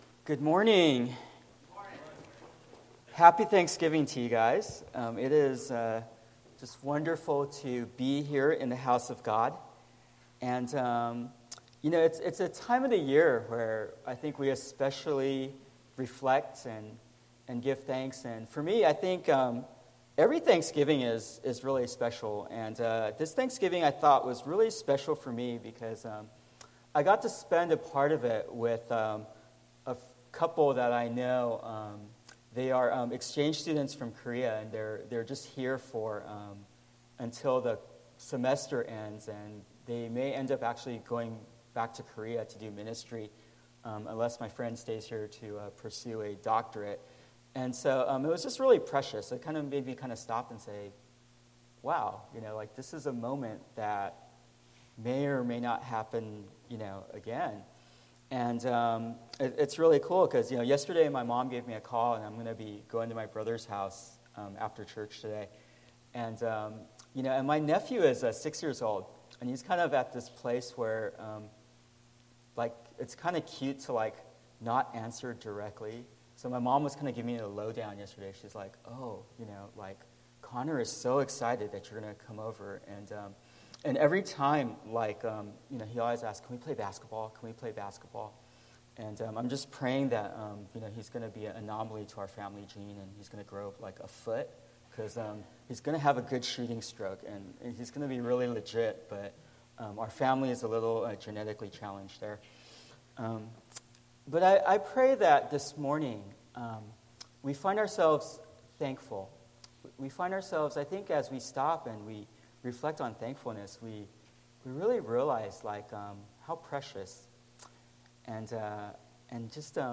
Bible Text: John 6:48-69 | Preacher